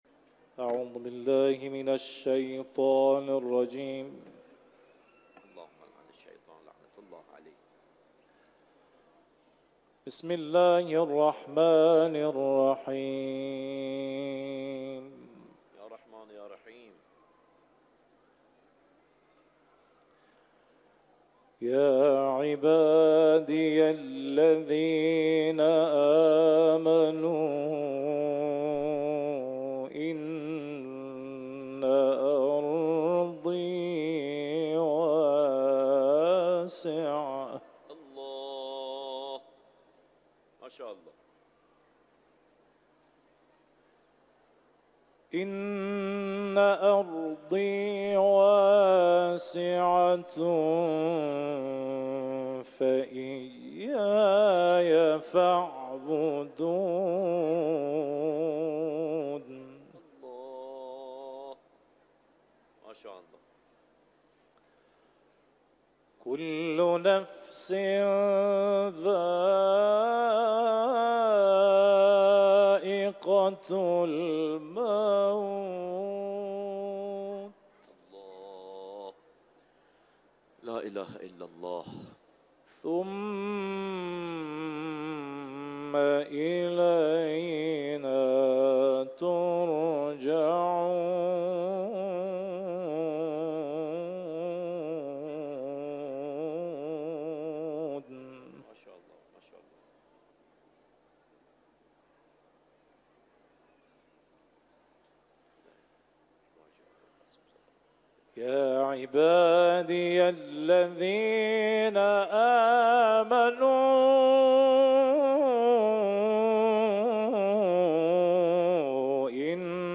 صوت تلاوت آیات ۵۶ تا ۶۲ از سوره «عنکبوت»